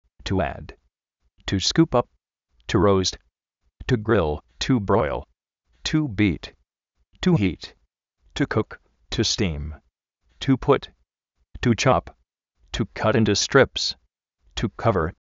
ád
skupáp
róust
gril, bróil
kat íntu strips